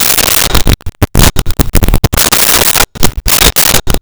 Pay Phone Coin Return Action
Pay Phone Coin Return Action.wav